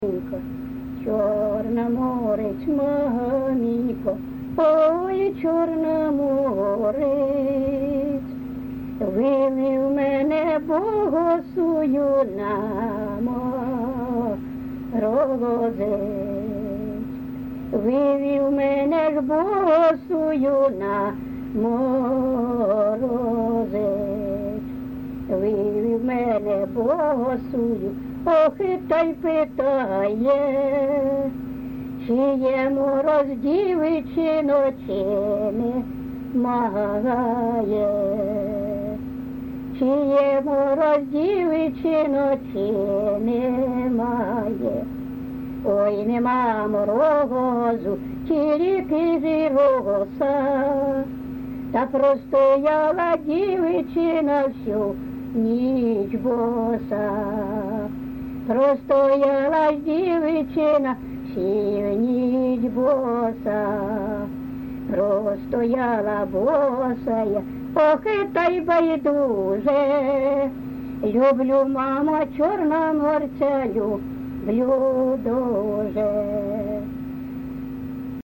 ЖанрПісні з особистого та родинного життя, Козацькі
Місце записус. Закітне, Краснолиманський (Краматорський) район, Донецька обл., Україна, Слобожанщина